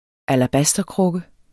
Udtale [ alaˈbasdʌ- ]